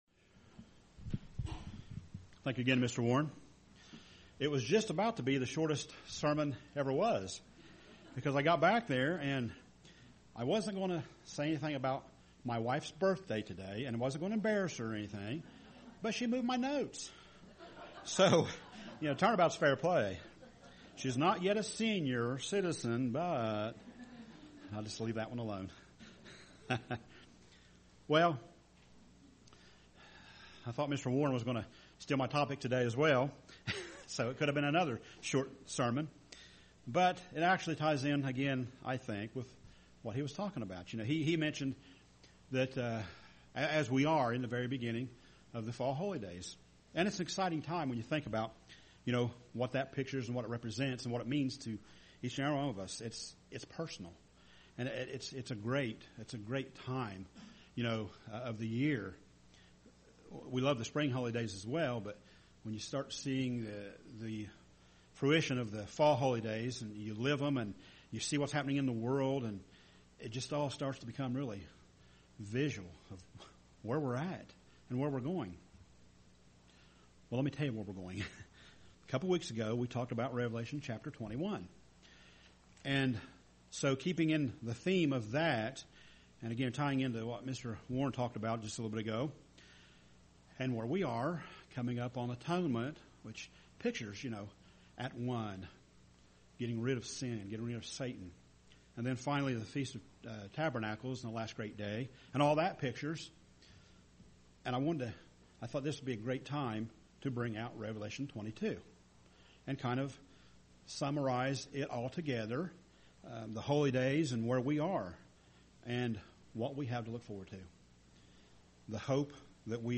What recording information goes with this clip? Given in Portsmouth, OH